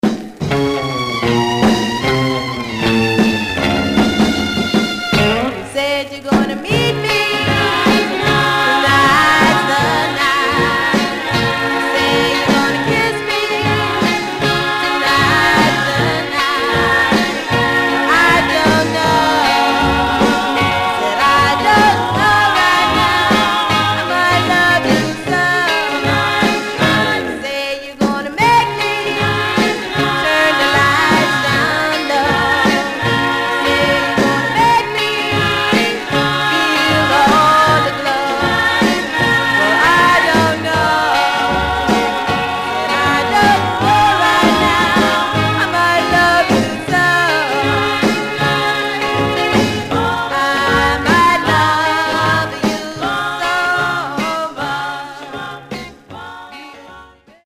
Surface noise/wear Stereo/mono Mono
Black Female Group